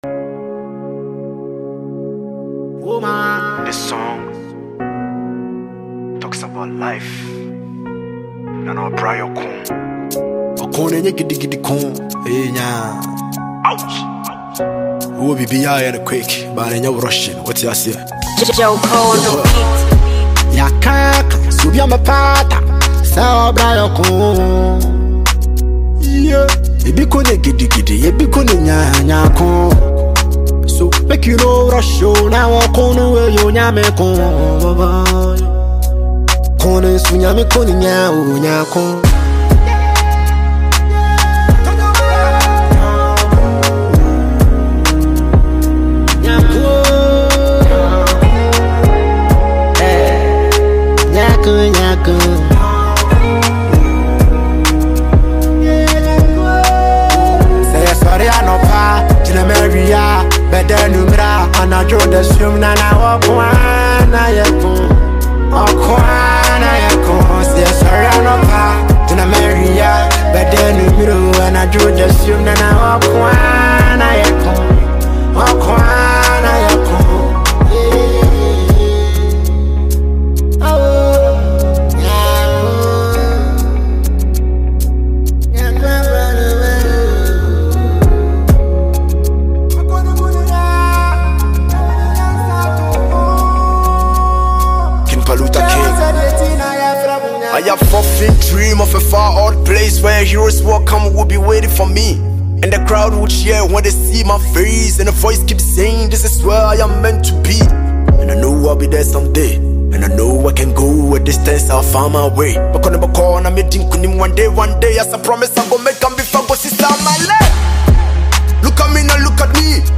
Ghanaian rapper and songwriter
catchy single